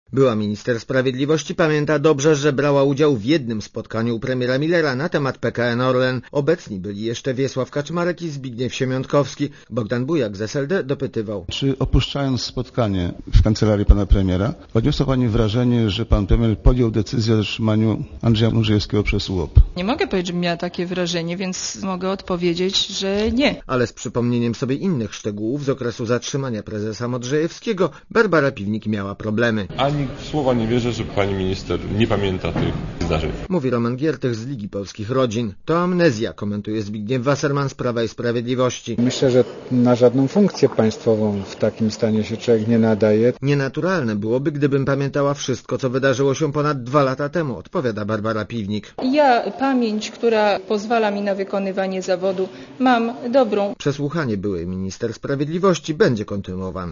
Relacja reportera Radia ZET Nie prowadzę dziennika, pamiętnika, nie mam notatek, opieram się na tym, co zapamiętałam - powiedziała Piwnik.